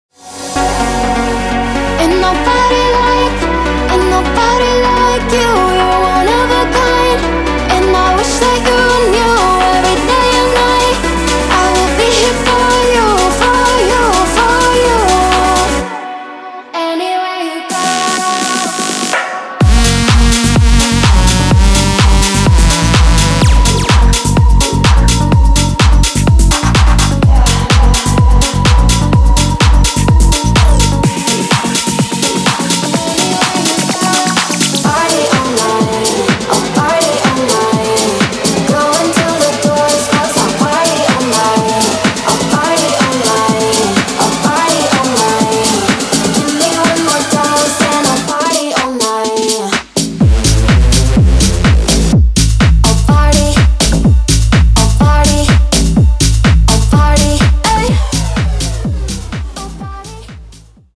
industry top-level royalty-free vocals